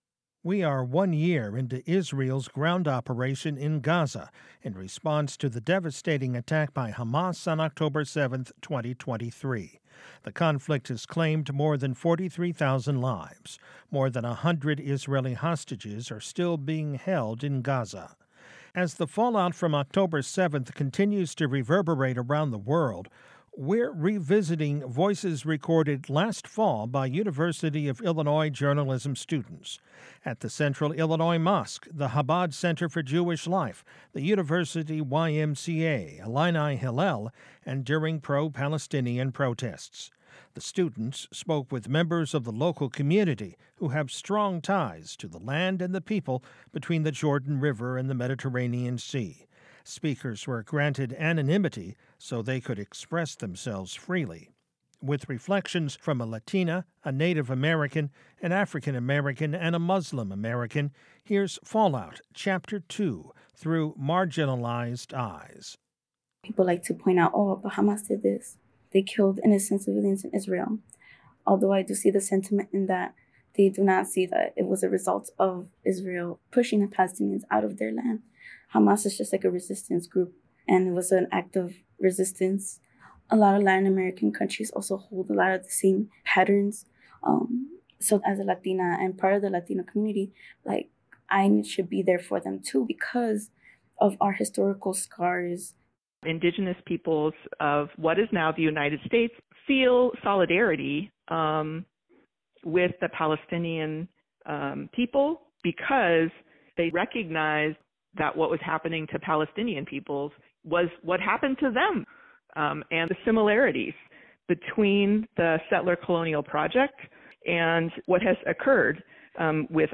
As the fallout from October 7 continues to reverberate around the world, we’re revisiting voices captured last fall by University of Illinois journalism students.
With reflections from a Latina, a Native American, an African American and a Muslim American, here’s Fallout, Chapter Two – Through Marginalized Eyes.